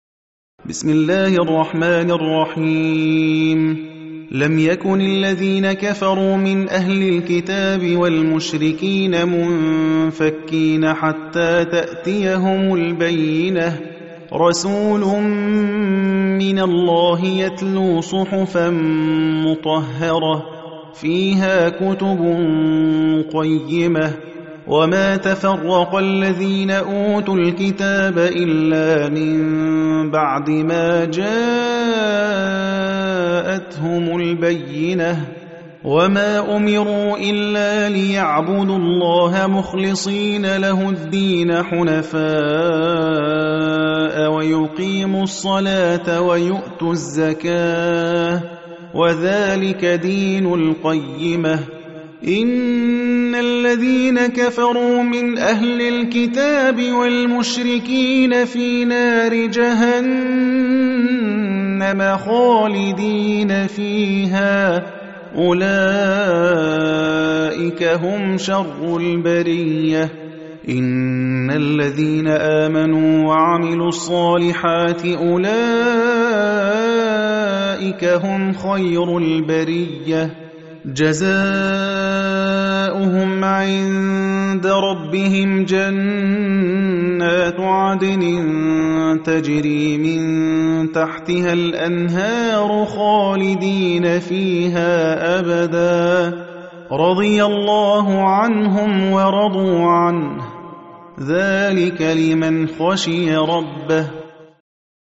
Hafs an Assim